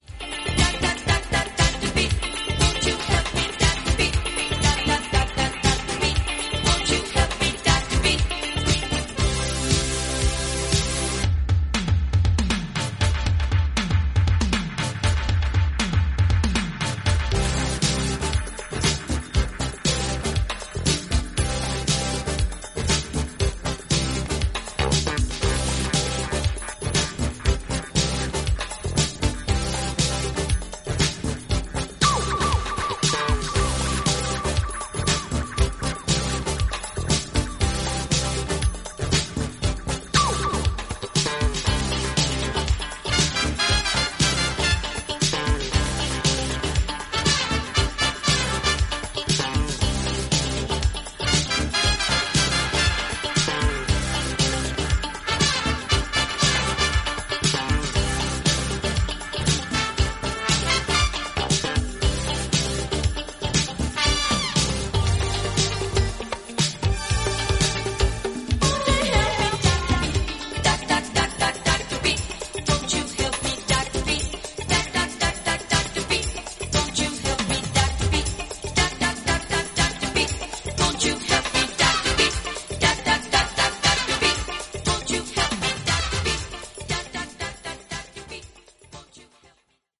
ロックからディスコ、ニューエイジまでを独自の感覚でエディットしています。